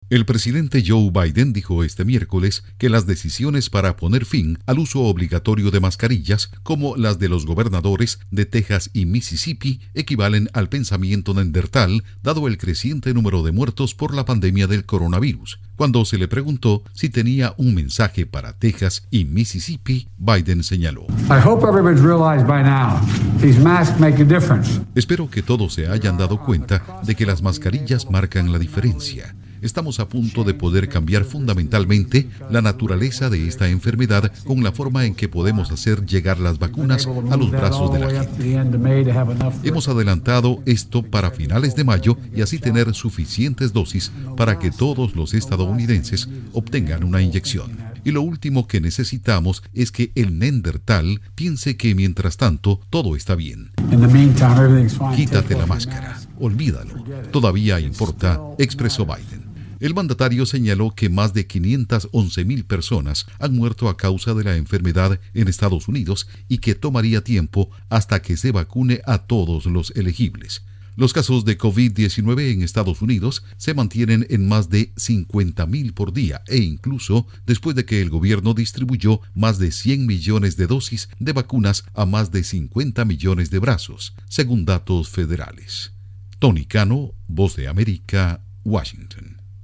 Biden dice que "es un gran error" que los estados de no obliguen a usar mascarillas en Estados Unidos. Informa desde la Voz de América en Washington